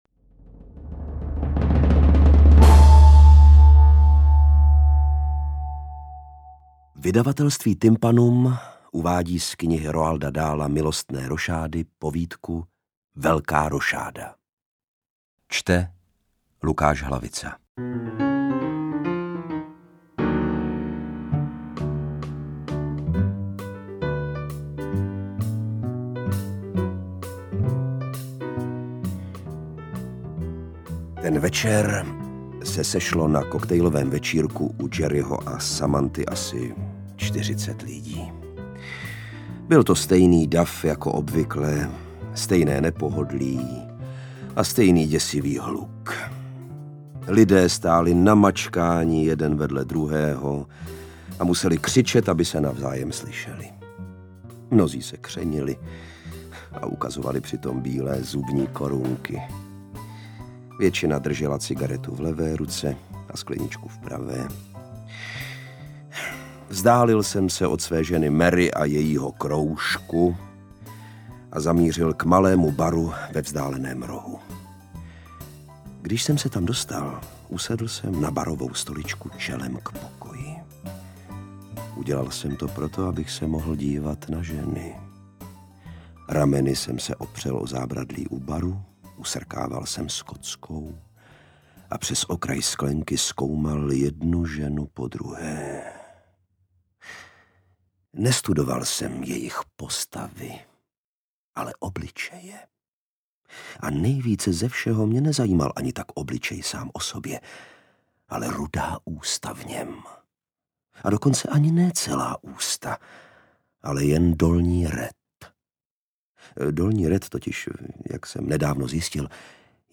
Interpret:  Lukáš Hlavica
AudioKniha ke stažení, 5 x mp3, délka 1 hod. 13 min., velikost 82,9 MB, česky